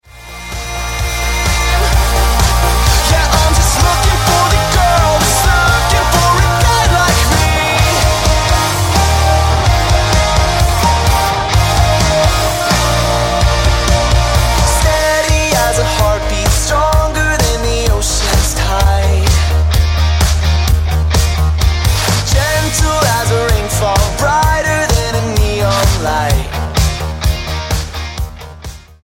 dance pop quintet
Style: Rock